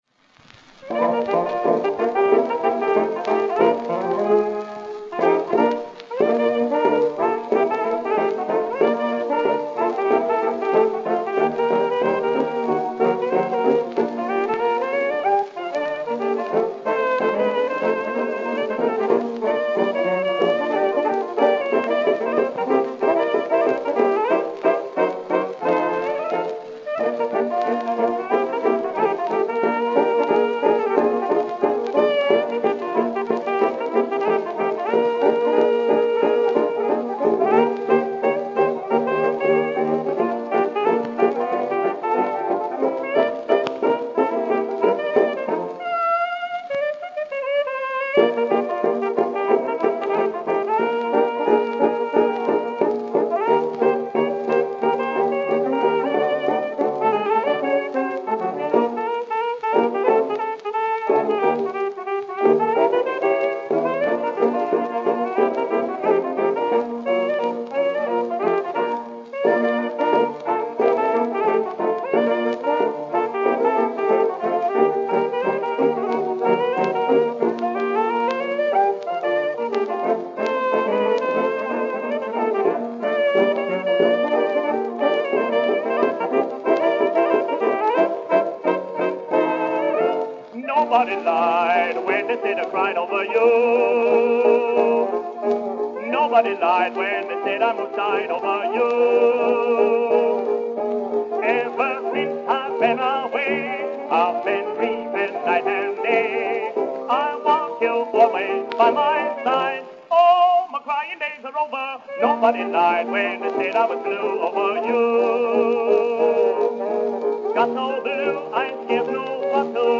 Vocal Chorus